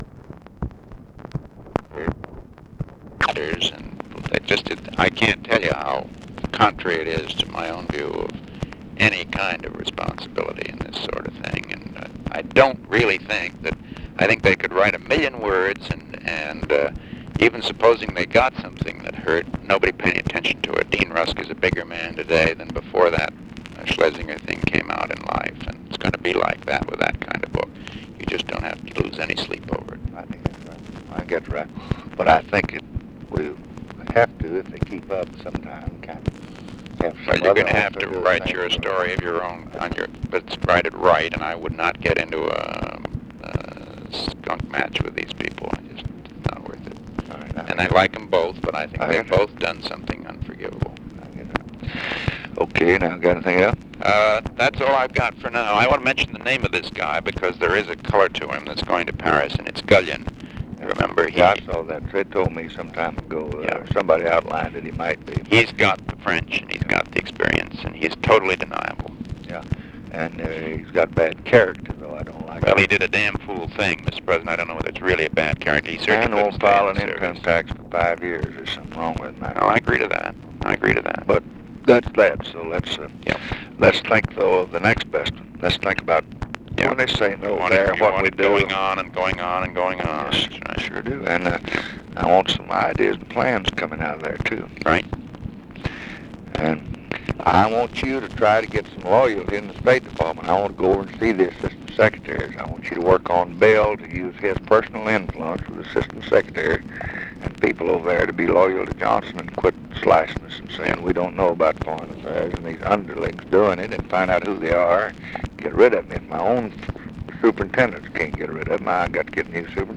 Conversation with MCGEORGE BUNDY, July 30, 1965
Secret White House Tapes